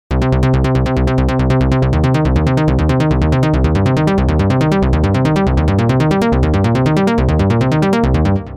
Press the PLAY button and play some chords on the keyboard - than more keys are held, than longer the sequence.
Examples: if C-3 is pressed, the sequencer plays C-2 and C-3.